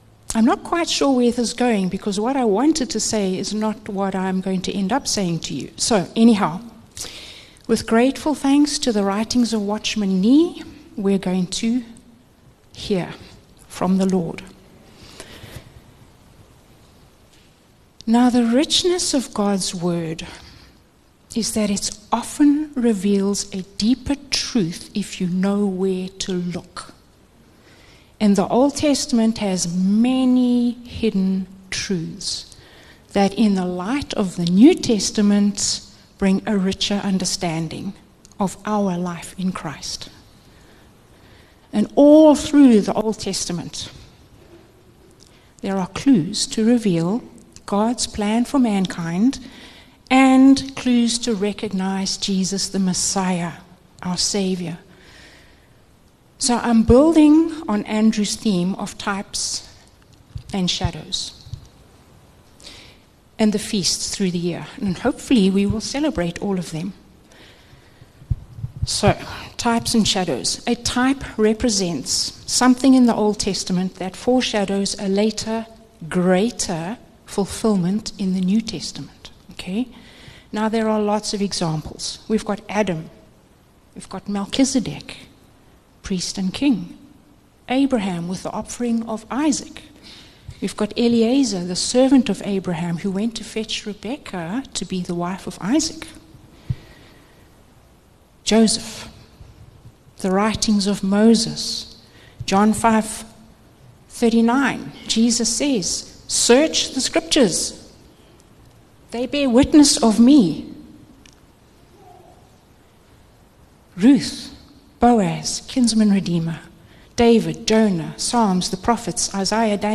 Upper Highway Vineyard Sunday messages